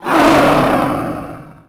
horror
Dragon Hurt Roar 3